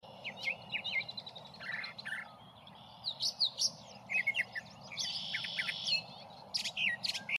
Brown Thrasher does a Robin sound effects free download
Brown Thrasher does a Robin and Red-bellied Woodpecker impression, a wonder of nature